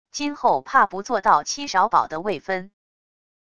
今后怕不做到戚少保的位分wav音频生成系统WAV Audio Player